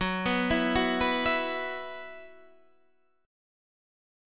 F#7sus4 chord